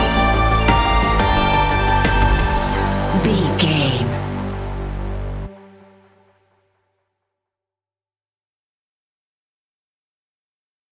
In-crescendo
Aeolian/Minor
tension
ominous
eerie
synthesizer
drum machine
ticking
electronic music